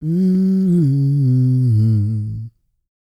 E-CROON P315.wav